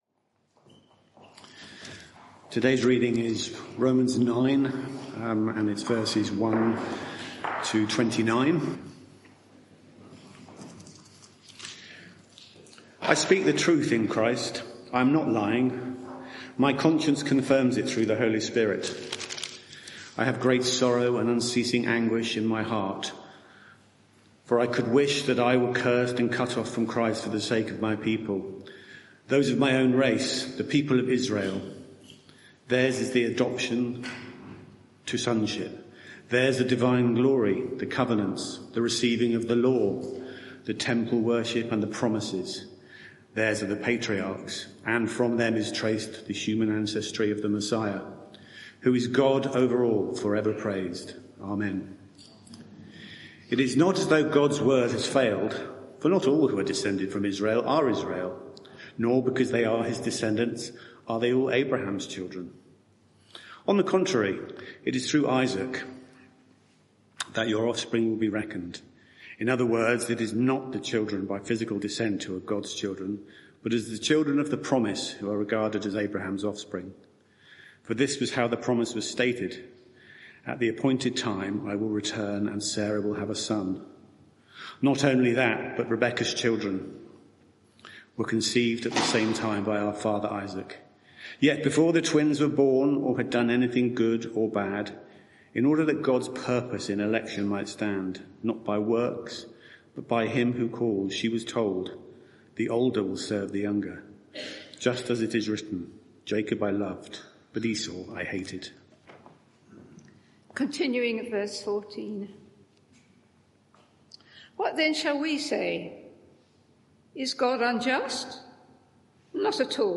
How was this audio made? Media for 11am Service on Sun 29th Sep 2024 11:00 Speaker